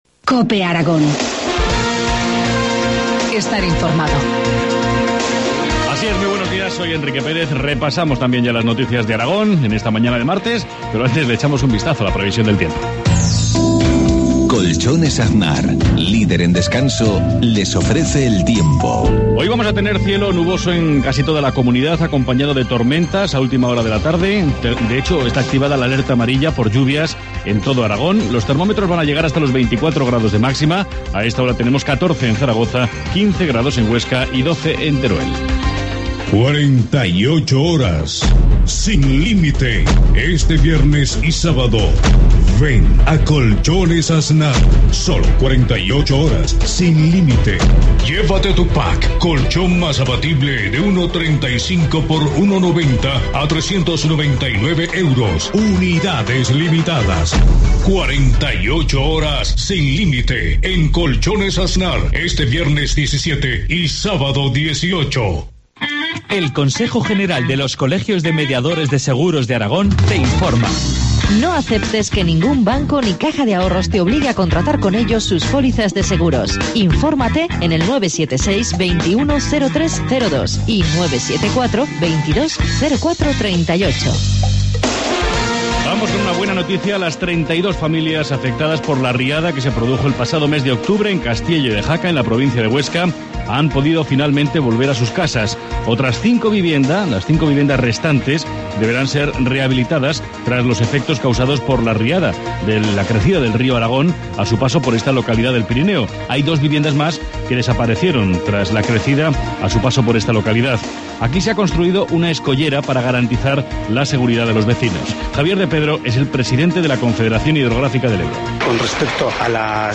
Informativo matinal, martes 14 de mayo, 7.53 horas